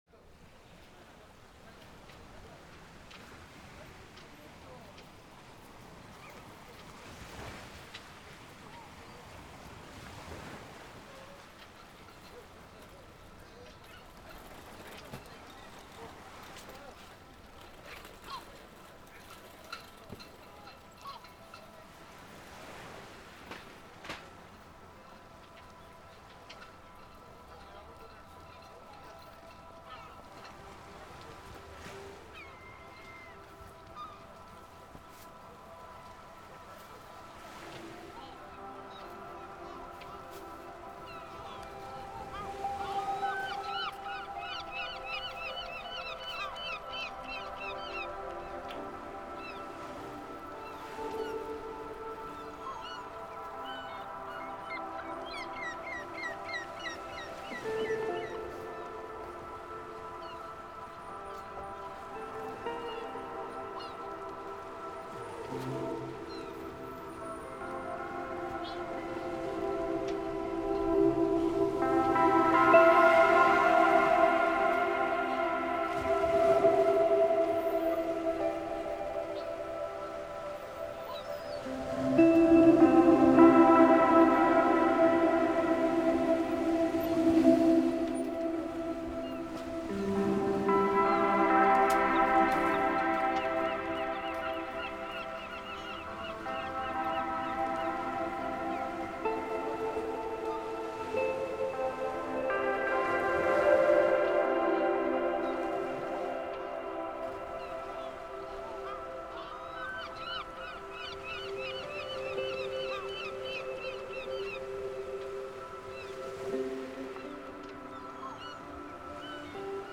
Ici, la musique émerge aussi du Sound Design. Dans cette séquence, ce sont des bruits de vagues et d’ambiance portuaire. La musique réagit directement au son (via des effets influencés appliqués sur les intruments)